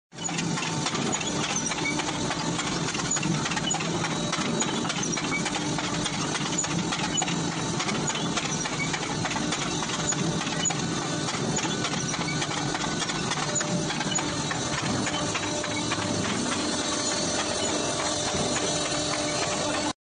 How Tunnel Boring Machine works sound effects free download